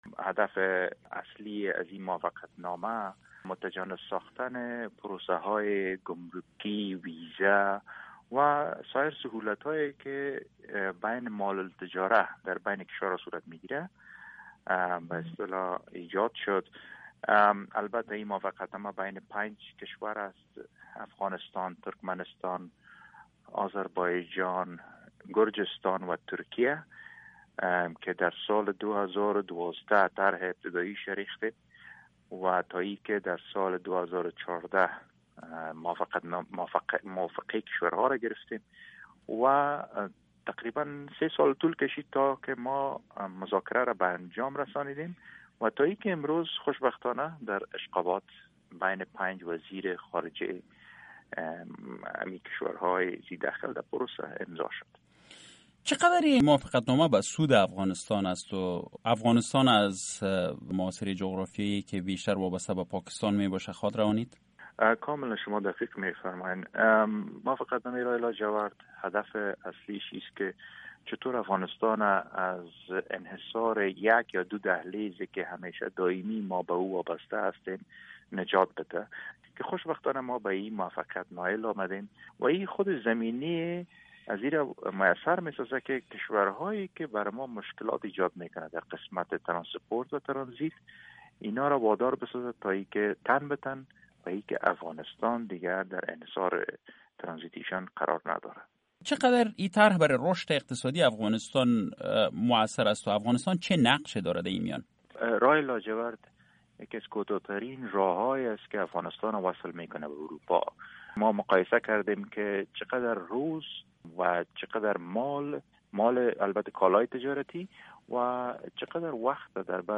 مصاحبه ها
شرح کامل مصاحبه با وحیدالله ویسی را از اینجا بشنوید!